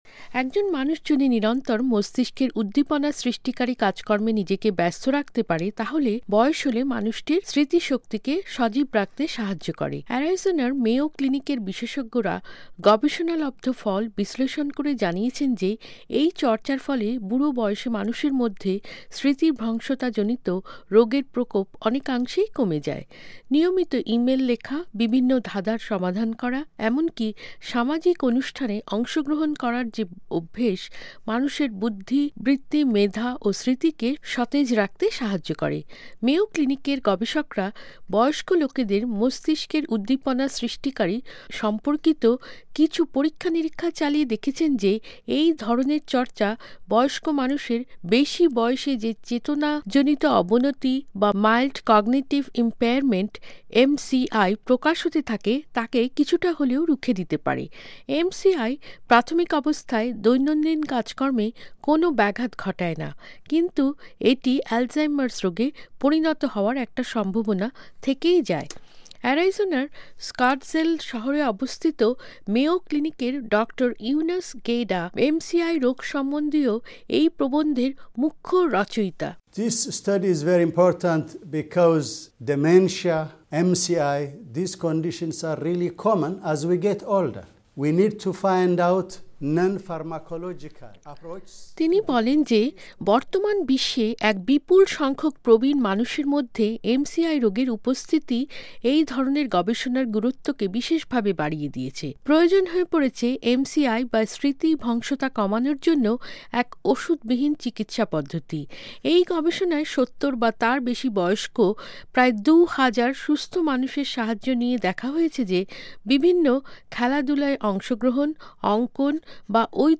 বিজ্ঞান ও প্রযুক্তি পর্বে প্রতিবেদনটি পড়ে শোনাচ্ছেন